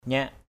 /ɲaʔ/ (cn.) nya v% (tr.) nhé! nhá!